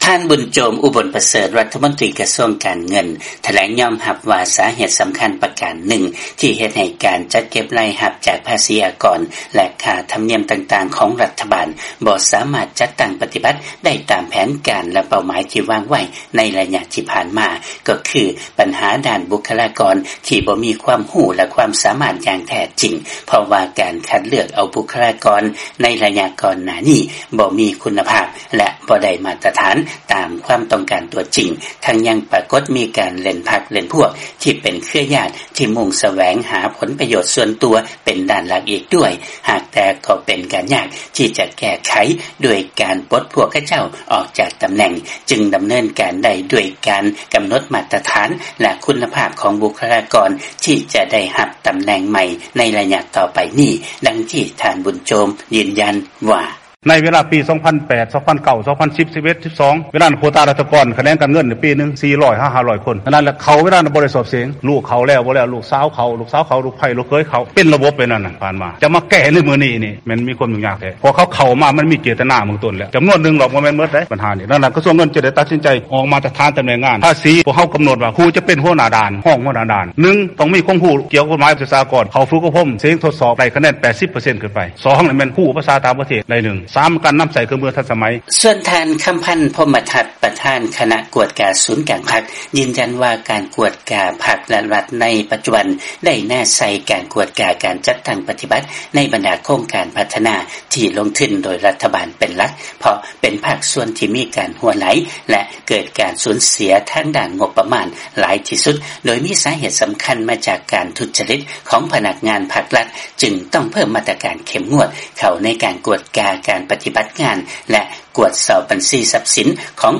ຟັງລາຍງານ ລັດຖະມົນຕີລາວຍອມຮັບວ່າ ມີພະນັກງານລັດຈຳນວນບໍ່ນ້ອຍປະຕິບັດໜ້າທີ່ໂດຍທຸຈະລິດ ໃນດ້ານພາສີອາກອນ